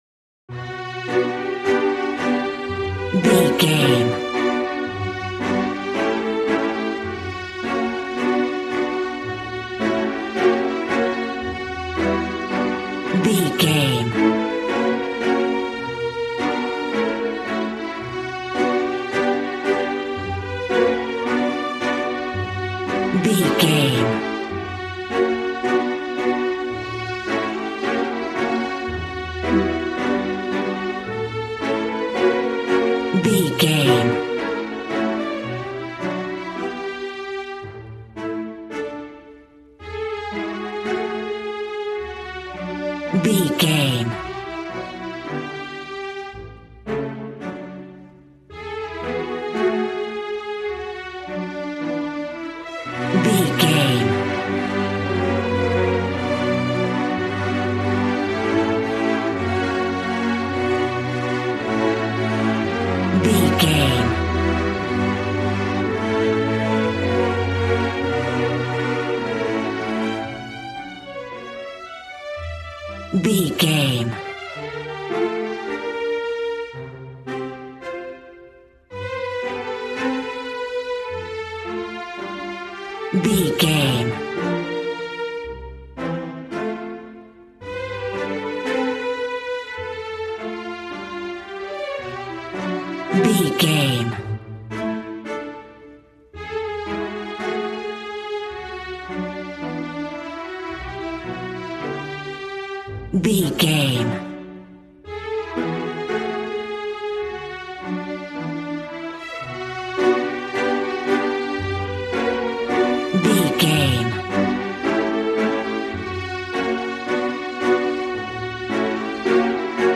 A warm and stunning piece of playful classical music.
Regal and romantic, a classy piece of classical music.
Aeolian/Minor
regal
piano
violin
strings